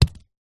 На этой странице собраны разнообразные звуки, связанные с манго: от мягкого разрезания ножом до сочного откусывания.
Манго - Альтернативный вариант 2